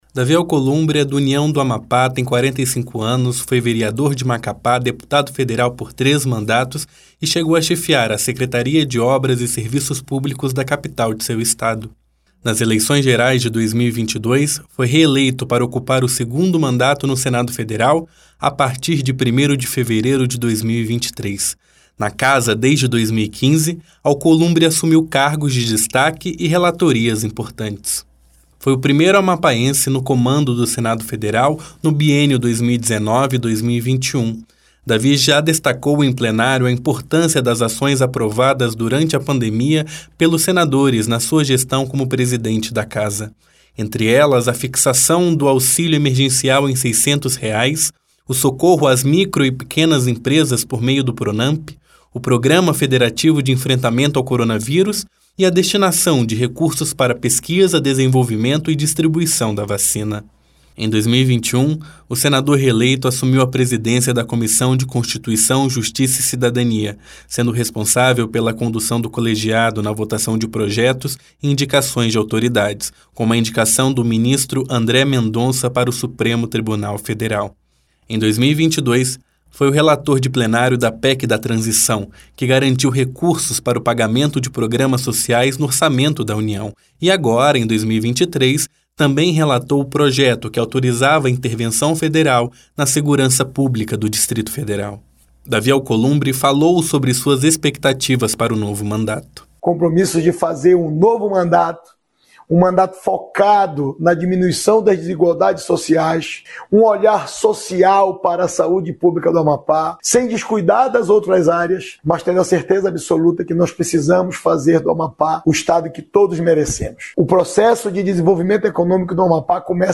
Em depoimento, afirmou que no novo mandato vai se dedicar à diminuição das desigualdades sociais e ao processo de desenvolvimento econômico do Amapá.